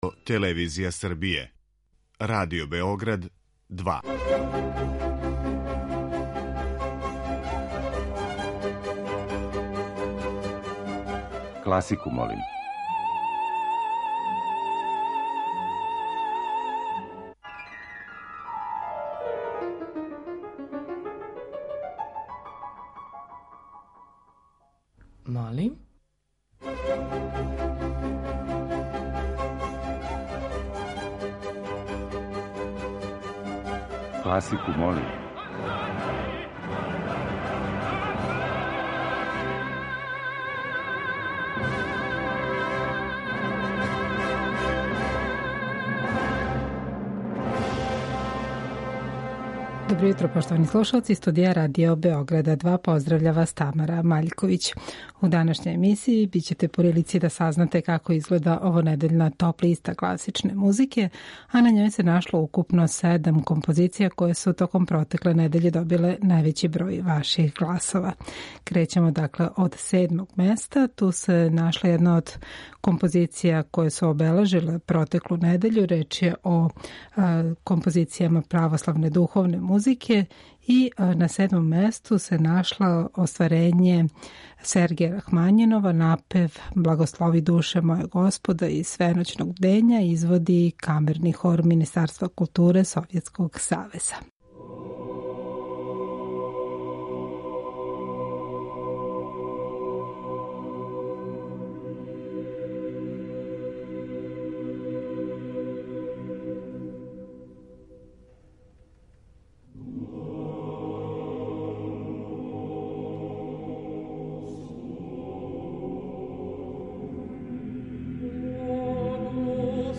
У недељи када прослављамо Божић по јулијанском календару, слушаћете одабране нумере православне духовне музике.
Уживо вођена емисија Класику, молим окренута је широком кругу љубитеља музике.